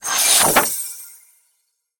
heal_scroll.ogg